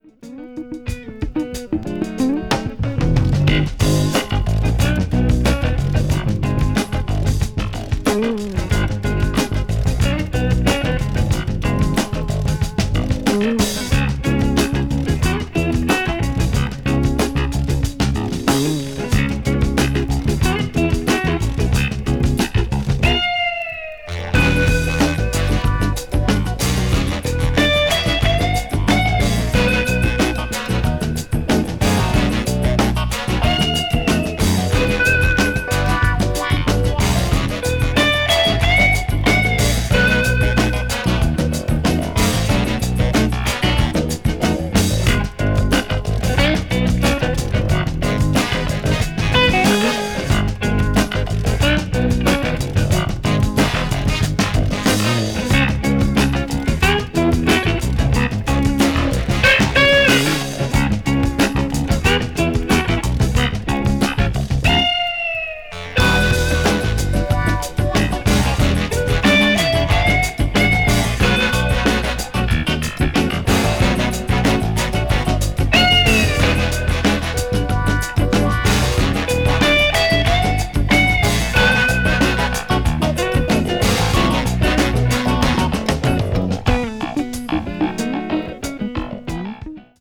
まずはタイトル曲のファンキーなグルーヴでノックアウト。